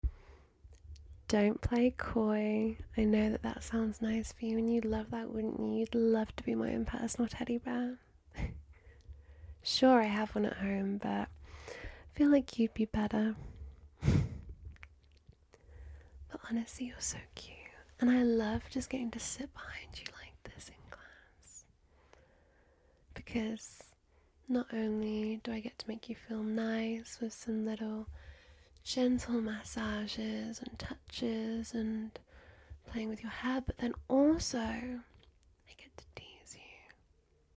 ASMR from your class crush sound effects free download